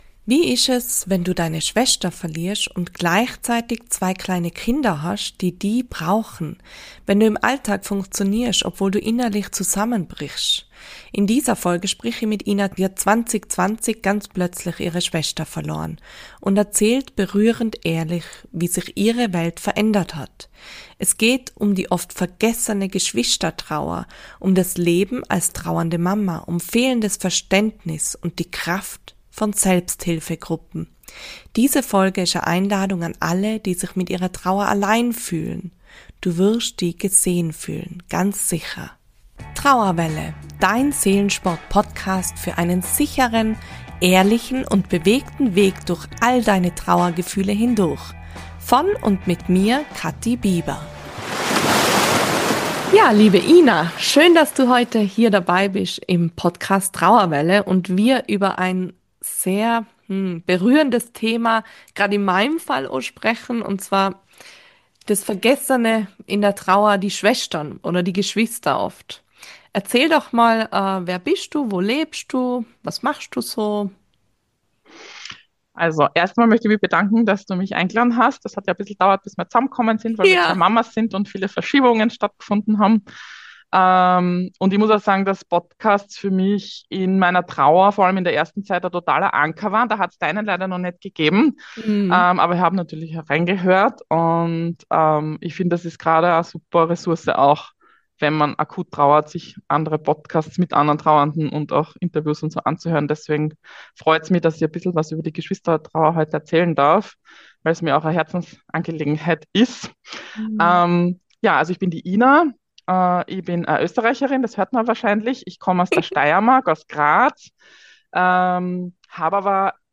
In diesem berührenden Gespräch